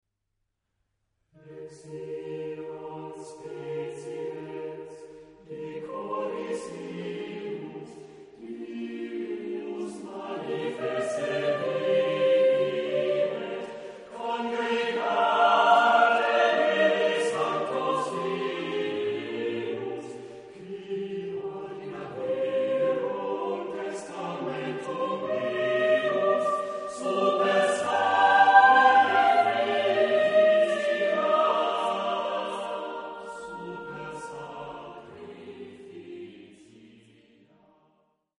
Epoque: 19th century
Genre-Style-Form: Romantic ; Sacred ; Motet
Type of Choir: SATB  (4 mixed voices )
Tonality: B minor